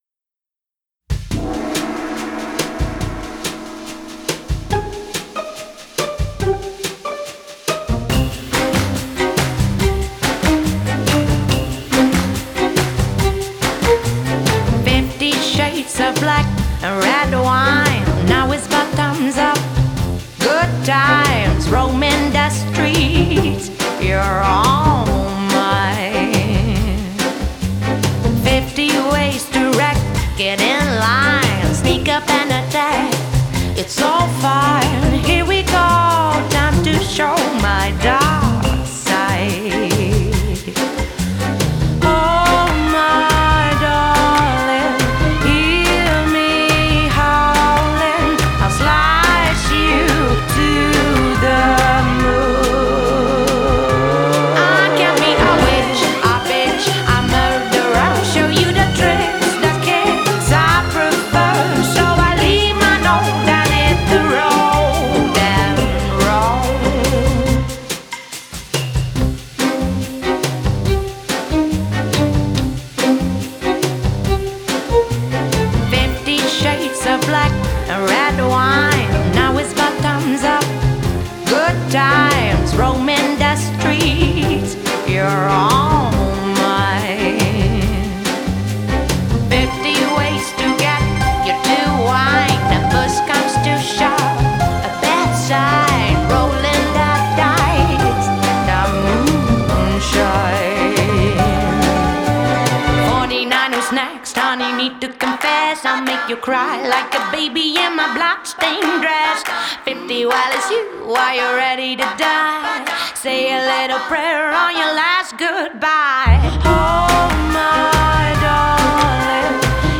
Genre: Indie Pop, Soul, Jazz